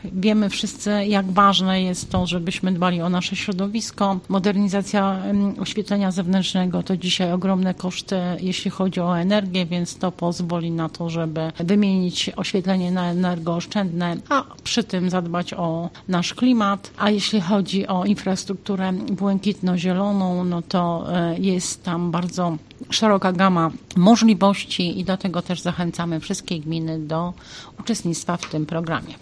Korzyści z tego programu są ogromne dodaje członkini zarządu: